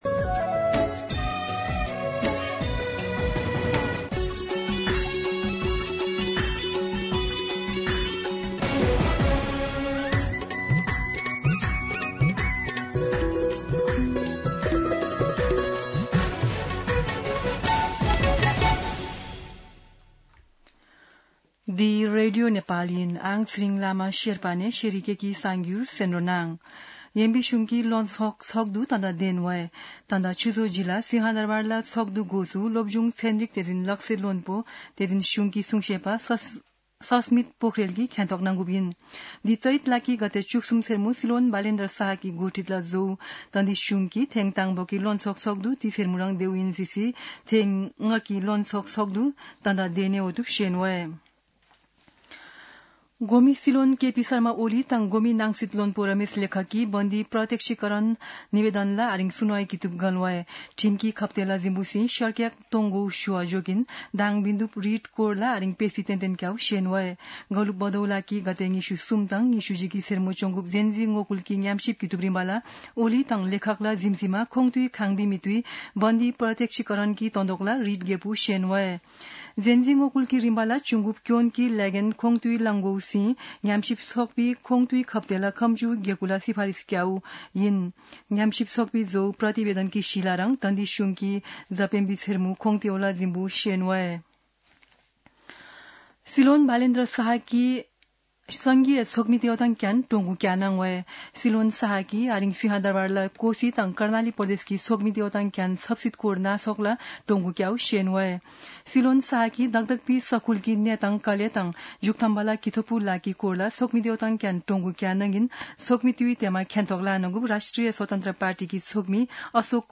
शेर्पा भाषाको समाचार : १६ चैत , २०८२
Sherpa-News-16.mp3